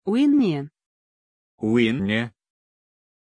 Pronunciation of Winnie
pronunciation-winnie-ru.mp3